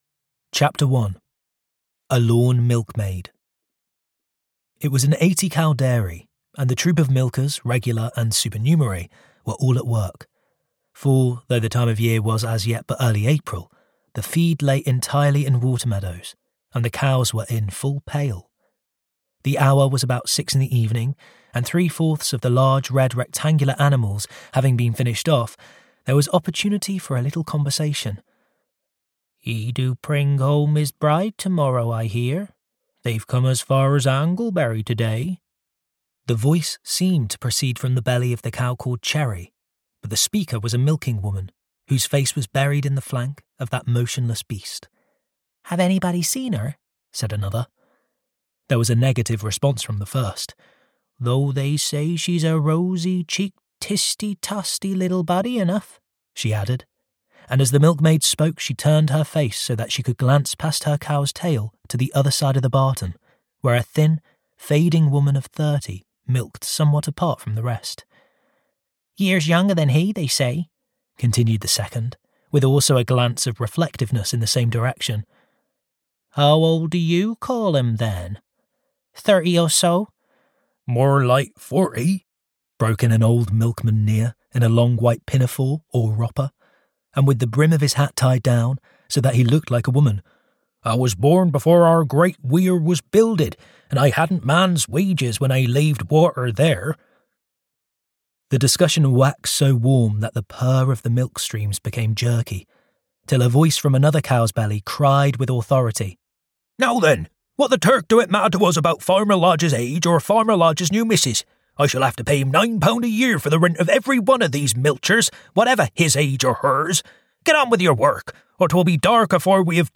The Withered Arm (EN) audiokniha
Ukázka z knihy